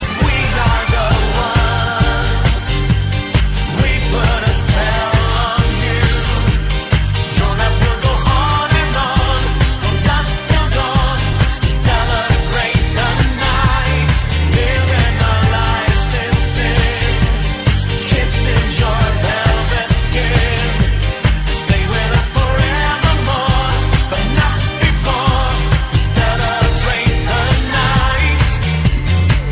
nice faster dance track